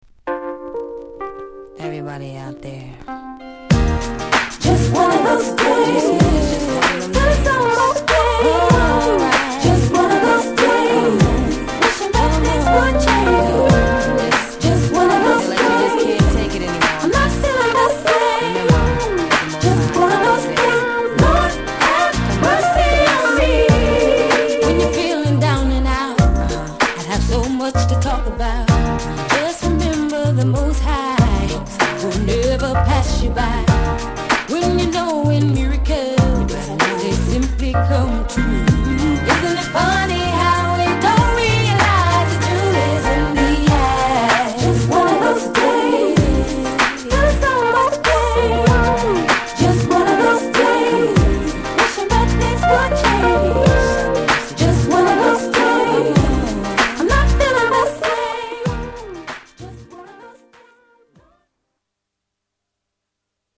Genre: #R&B
Sub Genre: #2000s